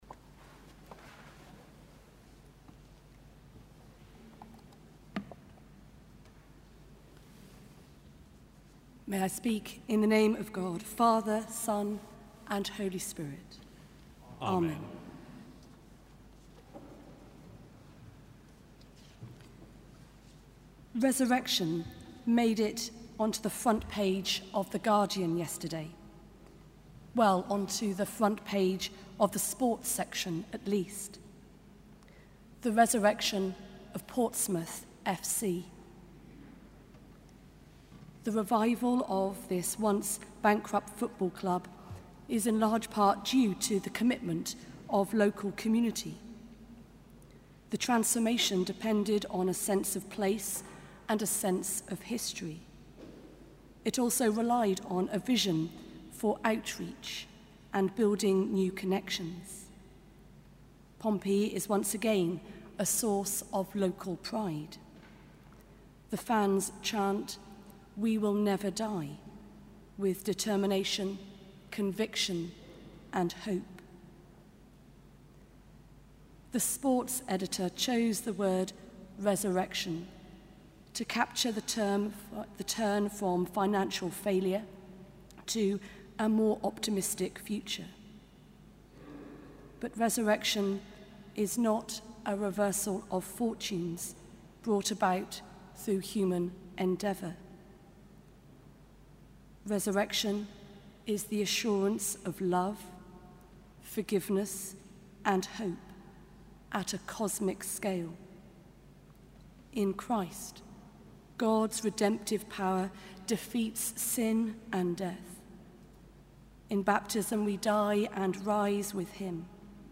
Sermon: Easter Day Mattins 2014